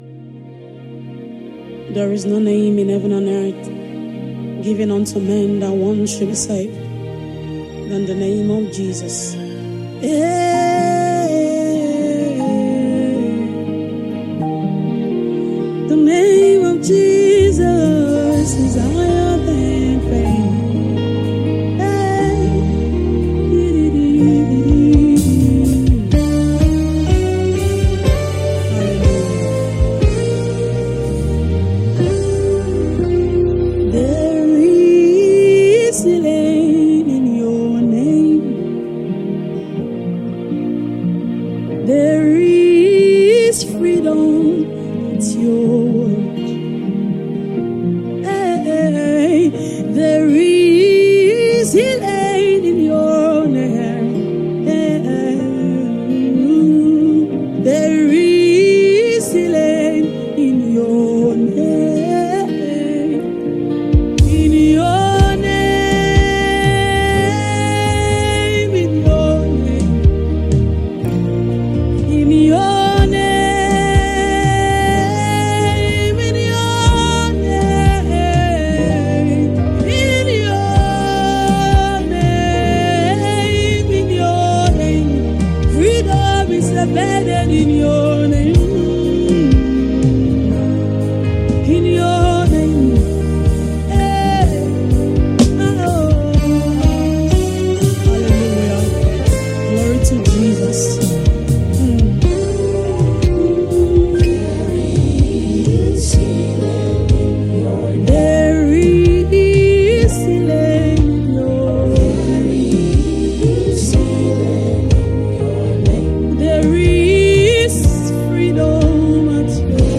Gospel Music
Nigerian Gospel Music